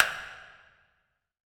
bouncehard4.wav